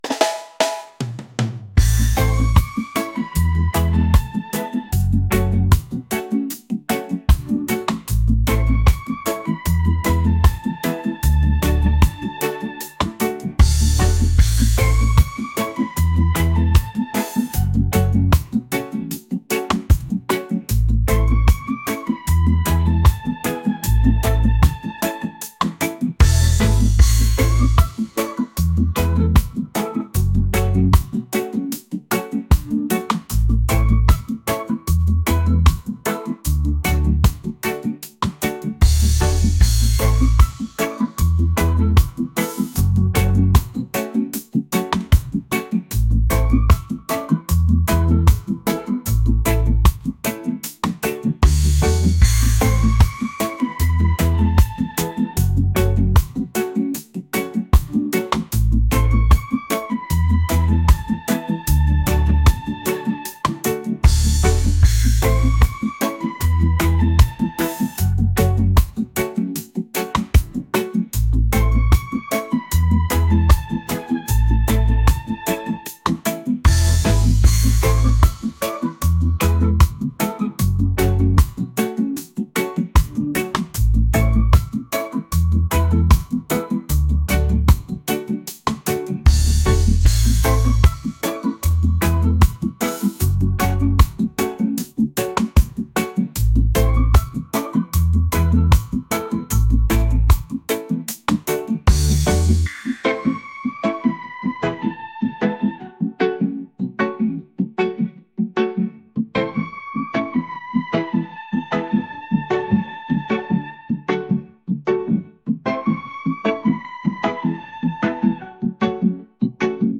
reggae | acoustic | soul & rnb